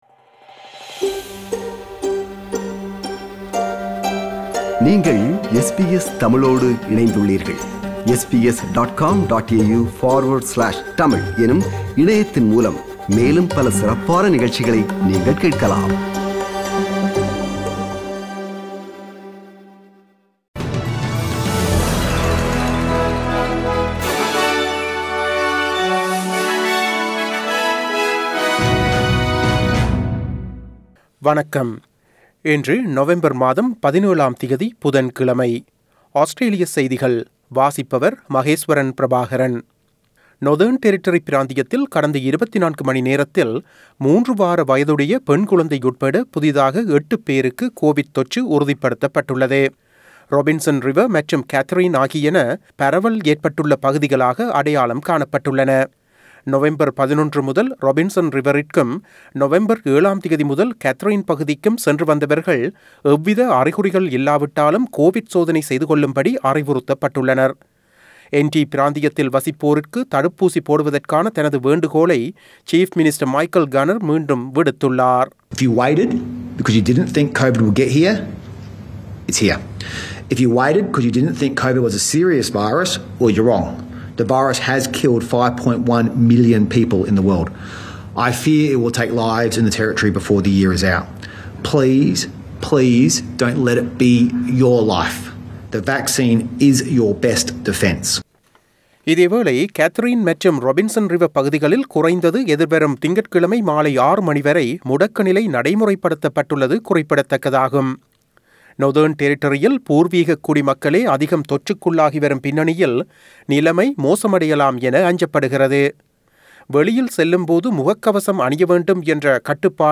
Australian news bulletin for Wednesday 17 November 2021.